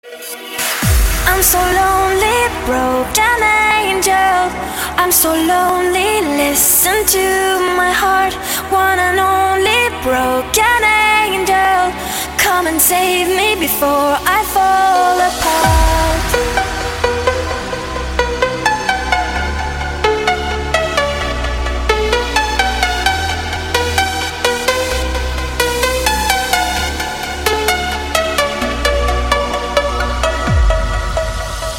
• Качество: 192, Stereo
спокойные
красивый женский голос
Спокойная композиция